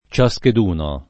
[ © a S ked 2 no ] pron.